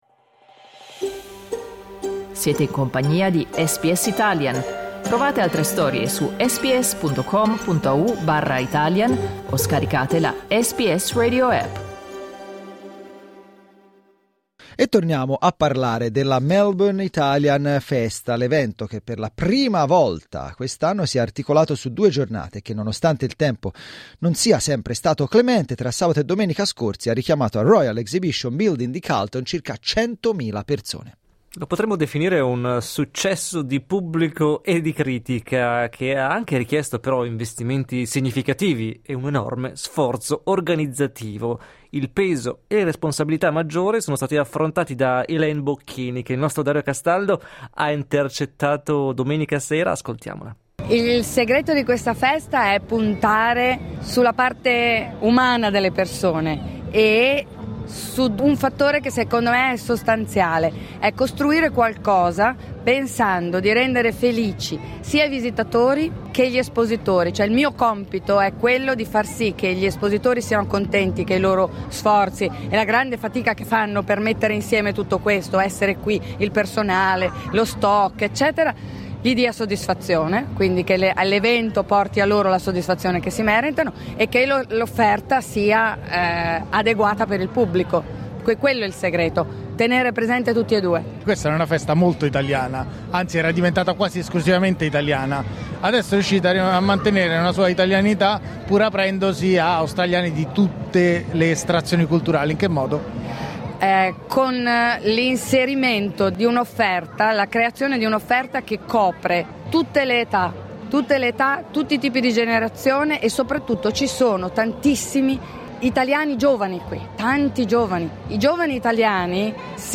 intervistata nel corso della Melbourne Italian Festa 2024